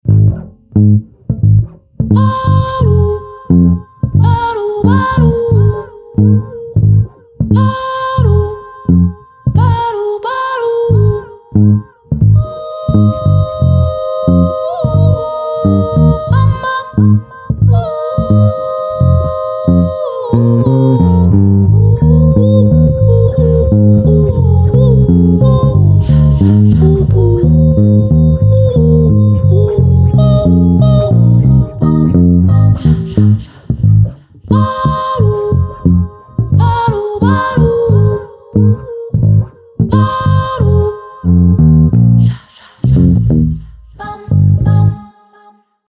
Acoustic, Alternative, R&B, Soul
Ab Major